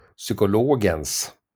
Ääntäminen
Ääntäminen Sweden (Stockholm) Haettu sana löytyi näillä lähdekielillä: ruotsi Käännöksiä ei löytynyt valitulle kohdekielelle.